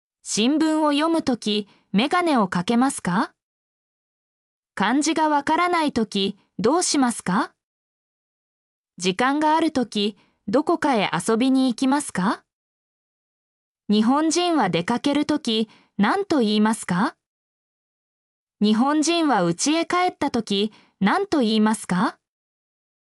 mp3-output-ttsfreedotcom-68_rJOhvxhZ.mp3